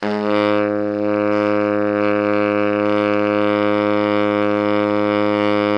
BARI PP G#1.wav